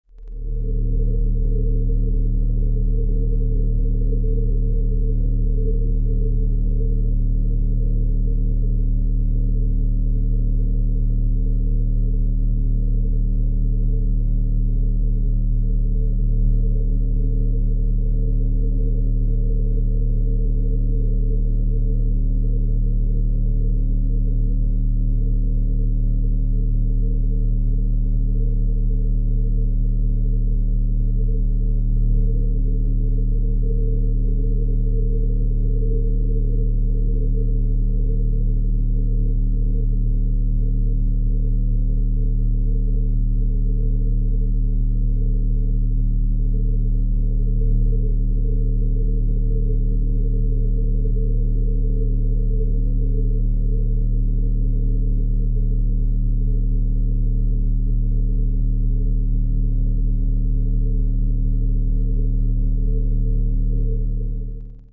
recharge.wav